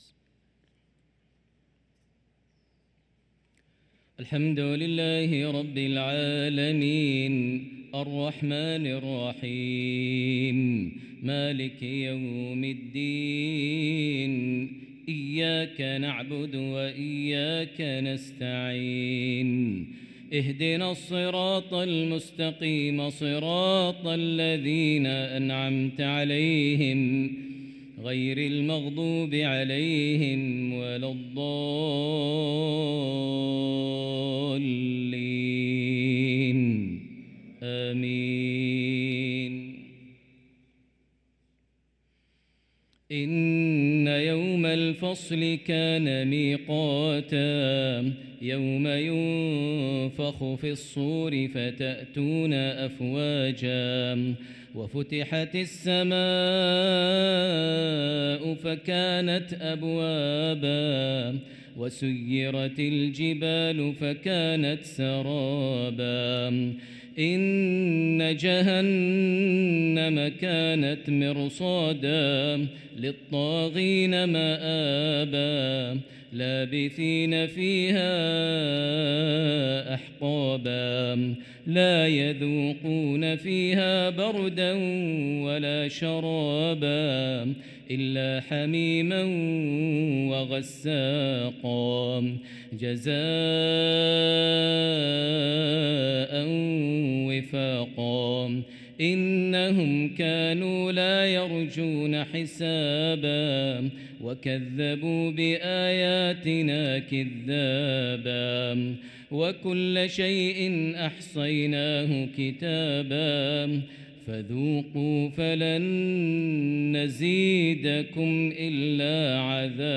صلاة المغرب للقارئ ماهر المعيقلي 19 صفر 1445 هـ
تِلَاوَات الْحَرَمَيْن .